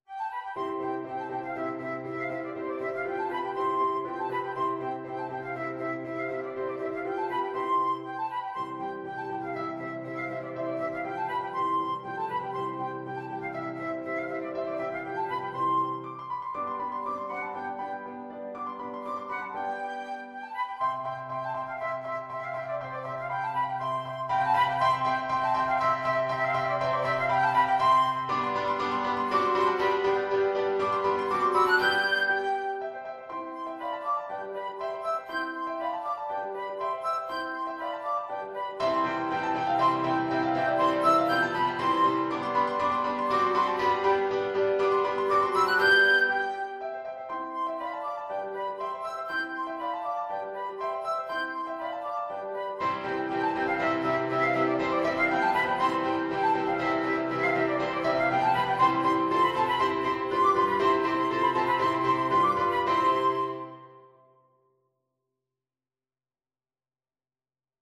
2/4 (View more 2/4 Music)
Classical (View more Classical Flute Music)